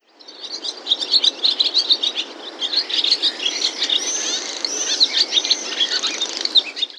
Les sons vont au-delà des simples cris des animaux. Ils restituent l’univers de ce moment de la journée, à la campagne.
03_hirondelles_Mix.wav